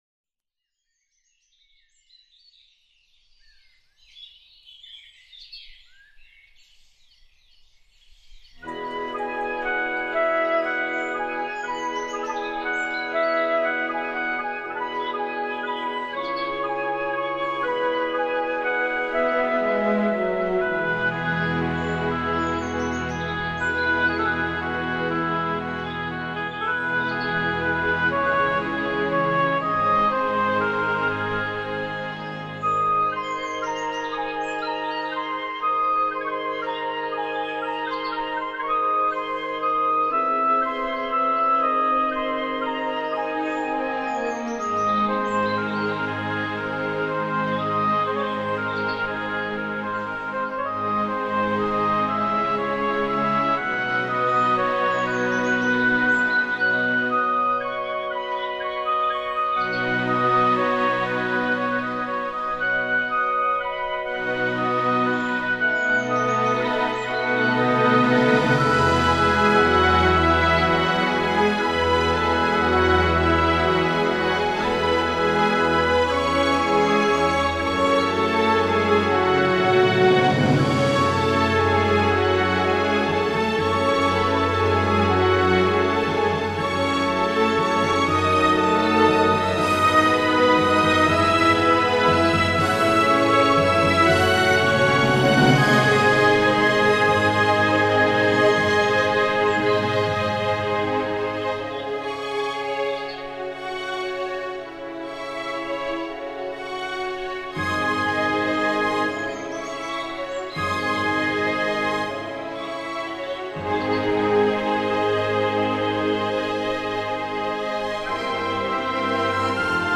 آهنگ بی کلام